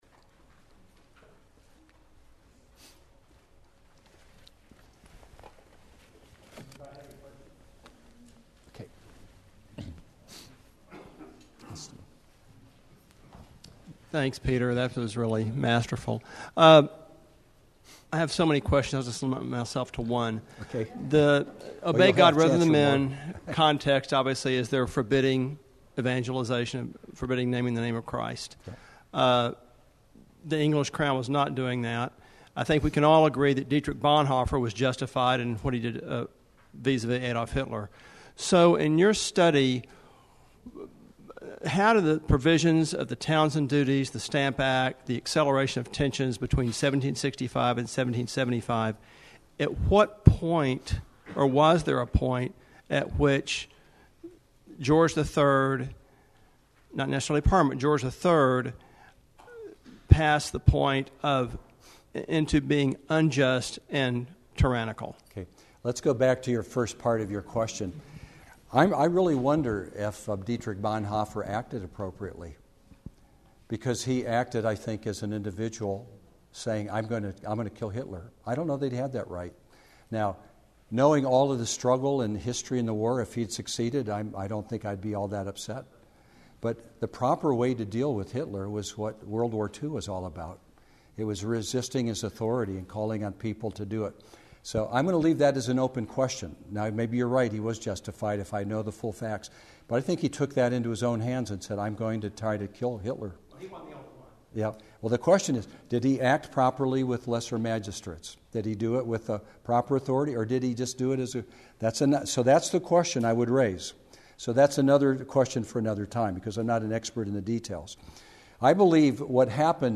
Q&A: Can the American Revolution be Justified in Light of Romans 13?